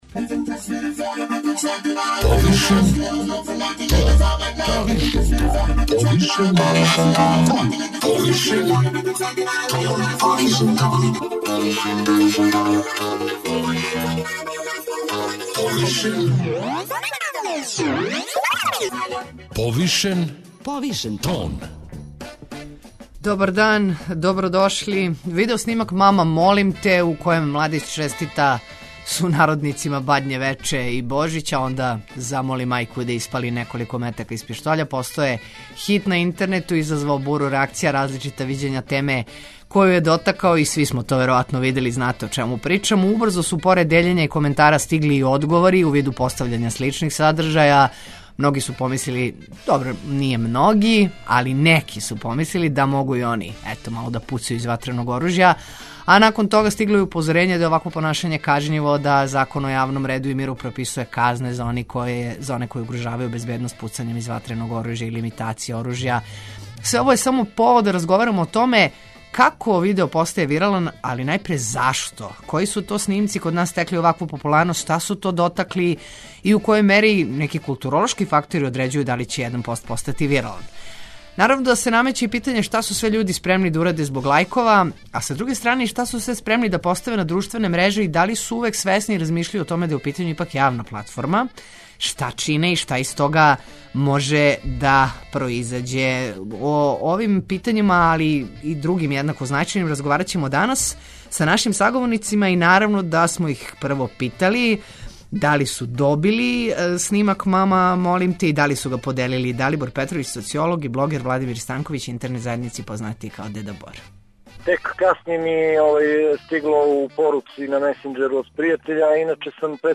преузми : 54.19 MB Повишен тон Autor: Београд 202 Од понедељка до четвртка отварамо теме које нас муче и боле, оне о којима избегавамо да разговарамо aли и оне о којима разговарамо повишеним тоном.